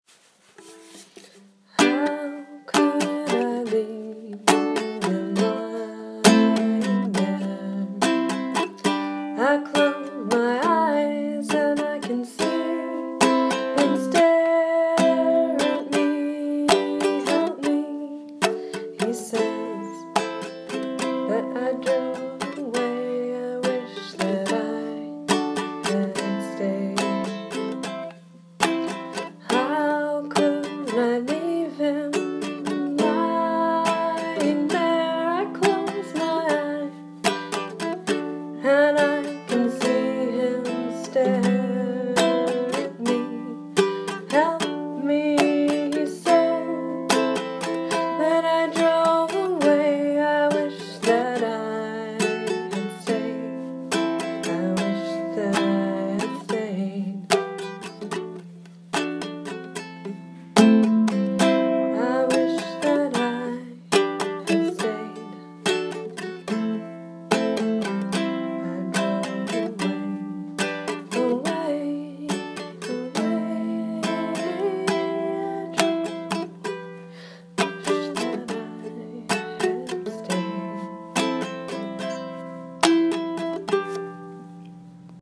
G-D-Am-C
It seems to convey more of the sadness I feel being in this place that has so much pain and not being able to fix it.